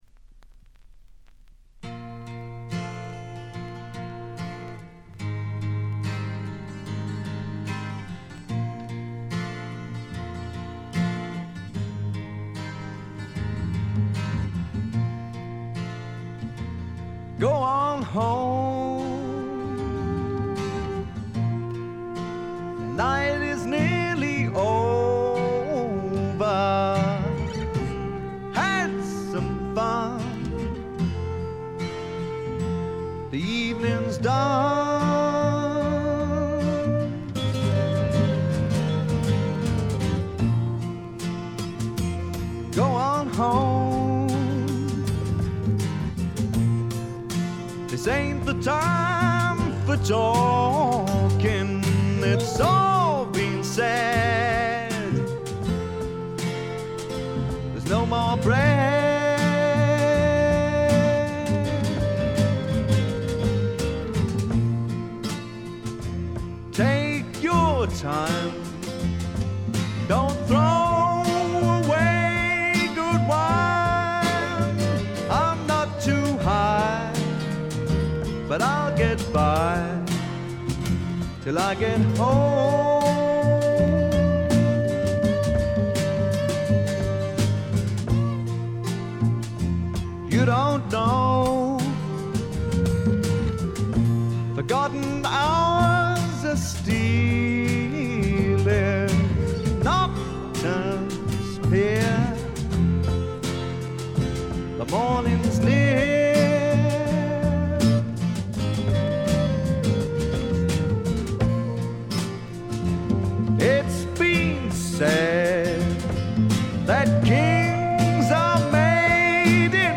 試聴曲は現品からの取り込み音源です。